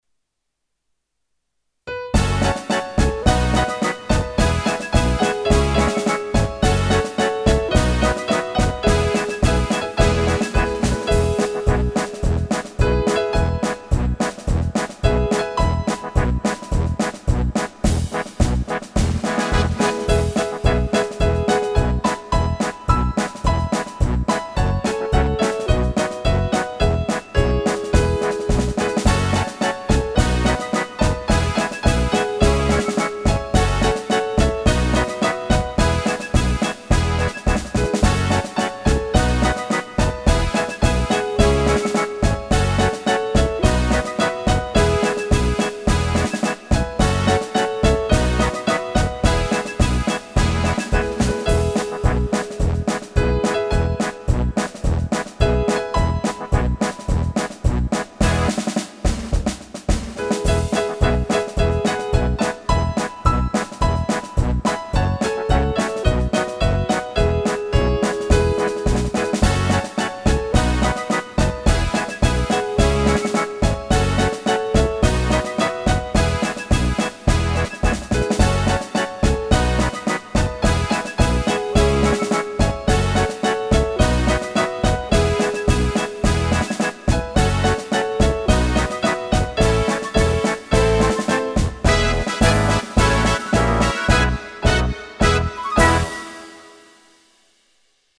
03-MINUS-ONE-MARS-TRAJUMAS.mp3